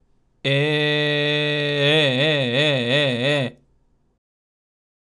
次に、グーの声で「え」の音、もしくは「あ」の音を伸ばしながら、見本音声のように5回発声します。
※見本音声(「え」の音)
h01_vibrato_G_e_slow.mp3